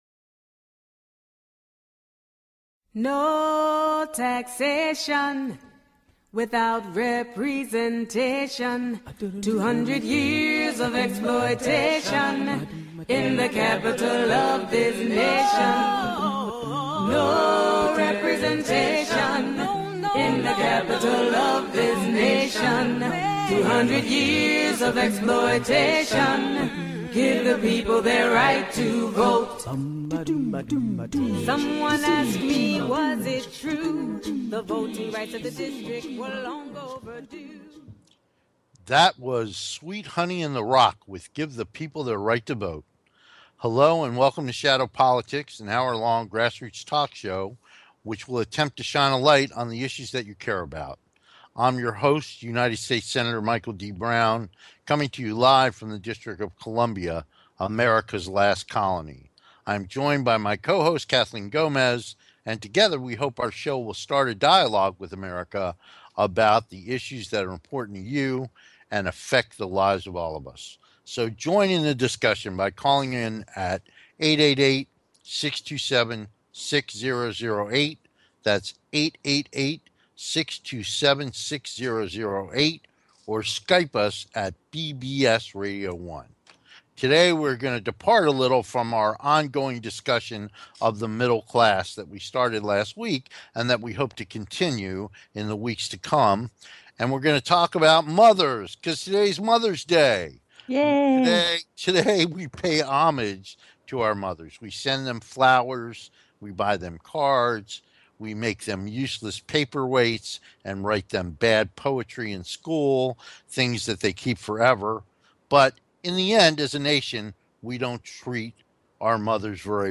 Talk Show Episode, Audio Podcast
Shadow Politics is a grass roots talk show giving a voice to the voiceless.